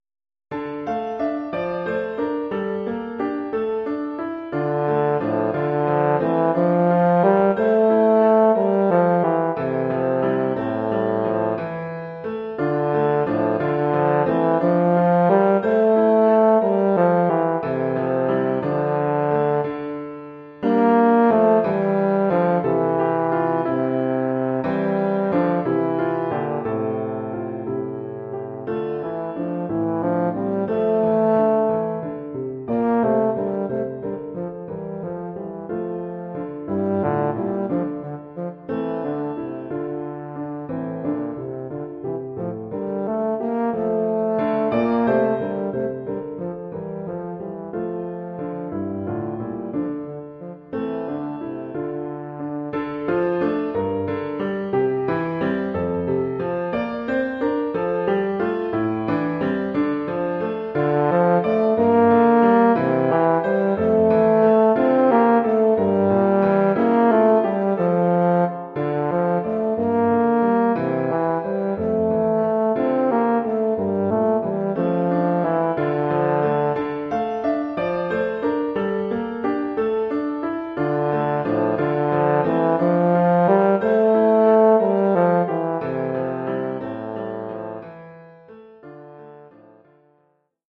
tuba et piano.